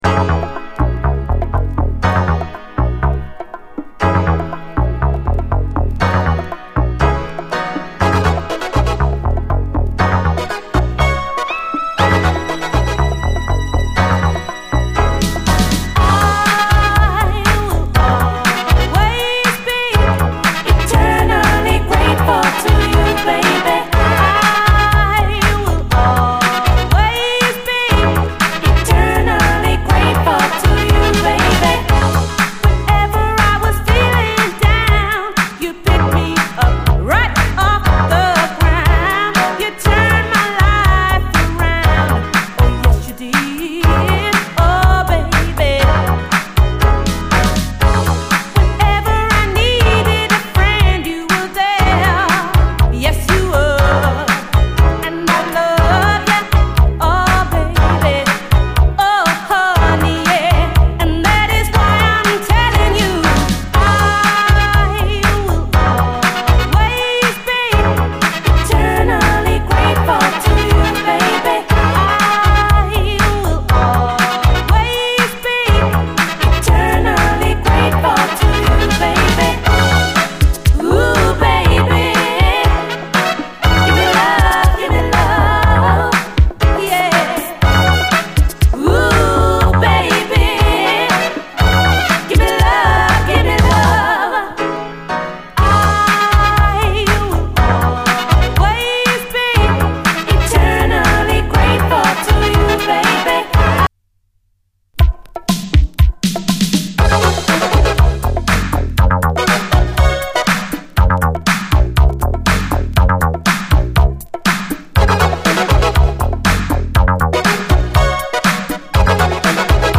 SOUL, 70's～ SOUL, DISCO, REGGAE
ディレイ処理、抜き差し具合がめちゃくちゃ気持ちいいグレイト・ダビー・ブギー！